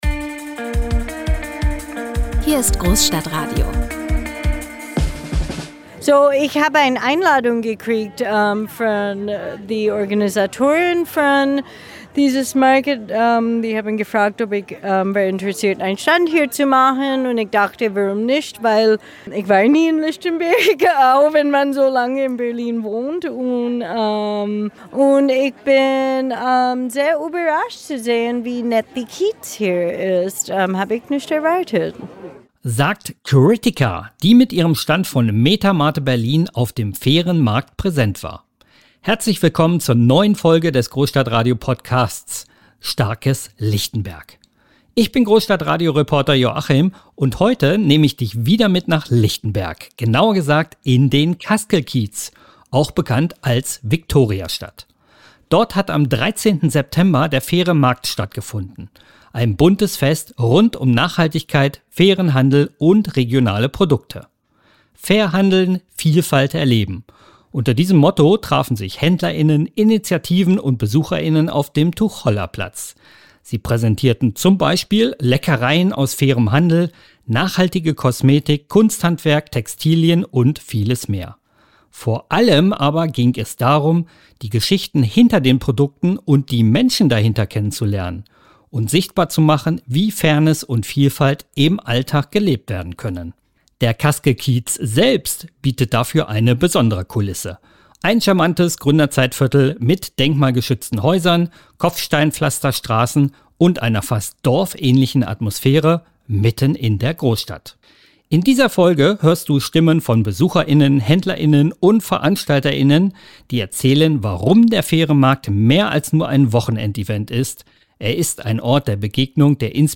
Dort hat im September der Faire Markt stattgefunden: ein buntes Fest rund um Nachhaltigkeit, fairen Handel und regionale Produkte.